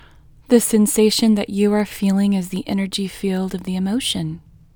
IN Technique First Way – Female English 3